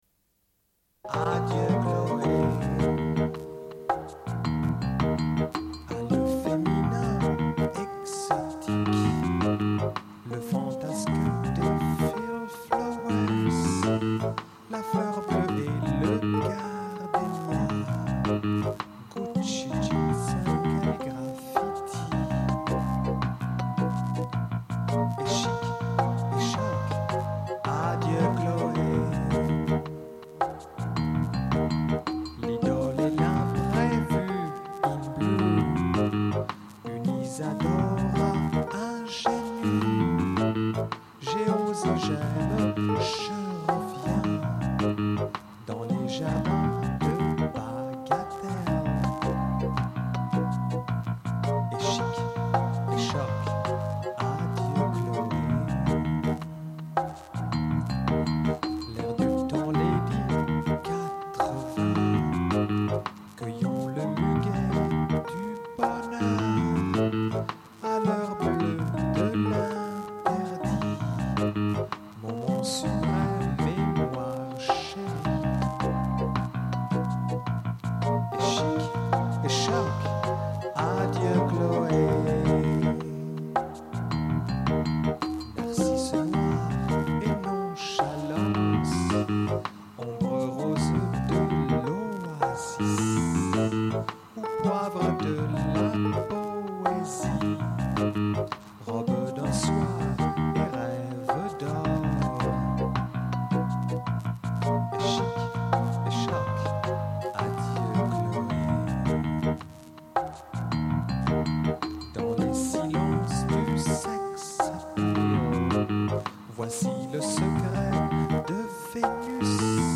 Une cassette audio, face B28:56